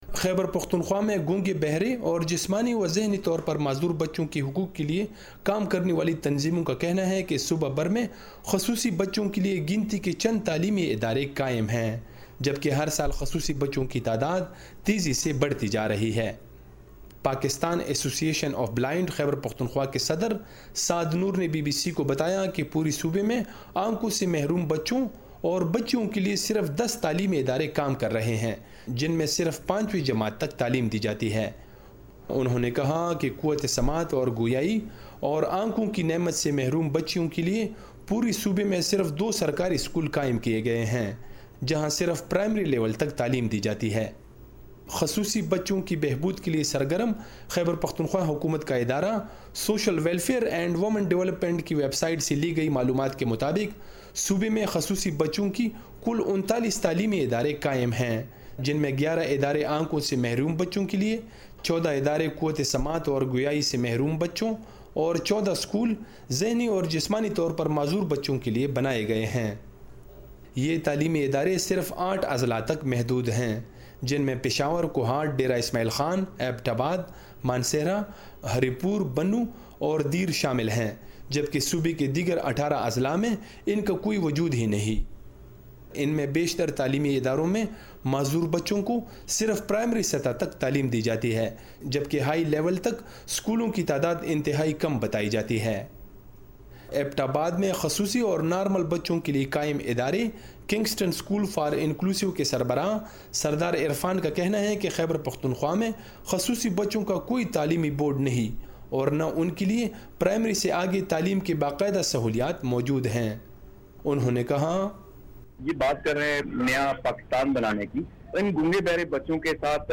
پاکستان کے صوبے خیبر پختونخوا میں برسراقتدار جماعت پاکستان تحریک انصاف کی حکومت دیگر شعبوں کے مقابلے میں تعلیم پر سب سے زیادہ بجٹ خرچ کررہی ہے لیکن قوت سماعت و گویائی سے محروم ، ذہنی اور جسمانی طورپر معذور بچوں کےلیے پرائمری سے آگے تعلیم کے مواقع انتہائی کم ہیں جسکی وجہ سے ہر سال سینکڑوں بے زبان بچے تعلیم کے بنیادی حق سے محروم رہ جاتے ہیں۔ پشاور سے نامہ نگار